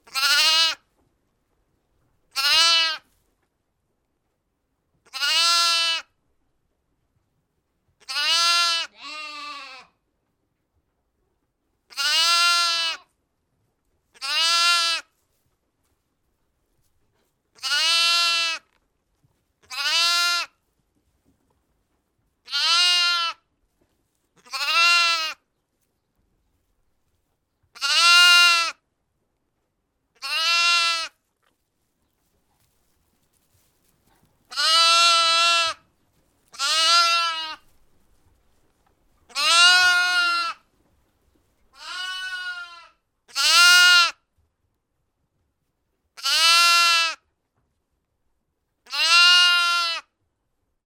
Звук пушистого ягненка до трех месяцев